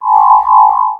ihob/Assets/Extensions/RetroGamesSoundFX/Hum/Hum30.wav at master
Hum30.wav